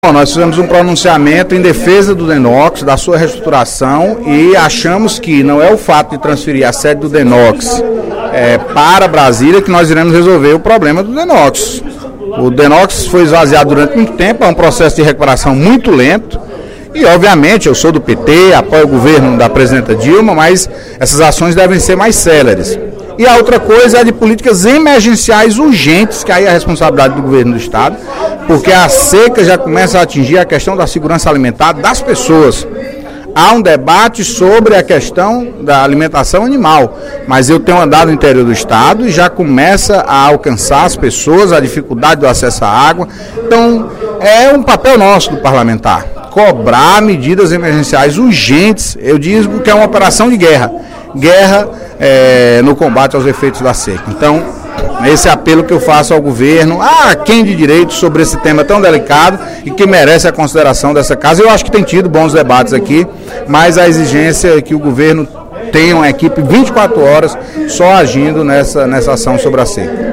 O deputado Antonio Carlos (PT) destacou, no primeiro expediente da sessão plenária desta terça-feira (02/04), a proposta de transferência da sede do Departamento Nacional de Obras Contra as Secas (Dnocs) para Brasília. O deputado se posicionou contra a medida e argumentou que o Dnocs trabalha especialmente com os estados do Nordeste e com o norte de Minas Gerais, e que seria incoerente deslocar sua sede.